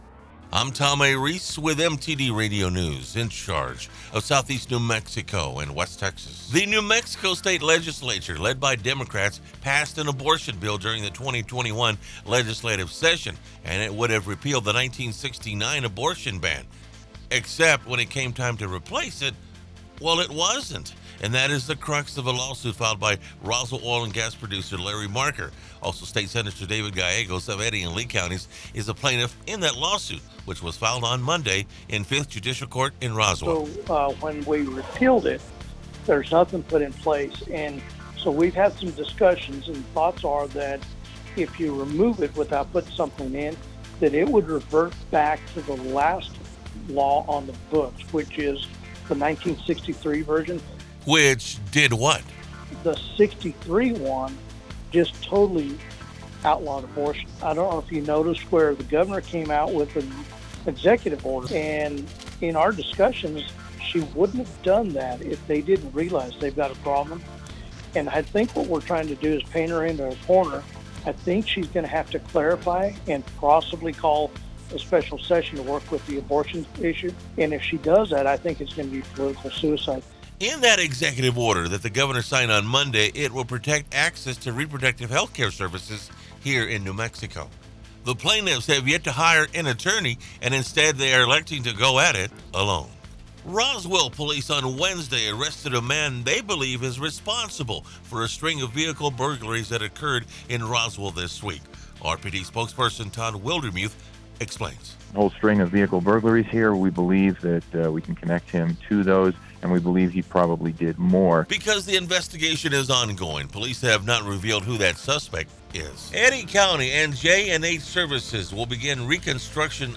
107.1 THE BLAZE NEWS JULY 1, 2022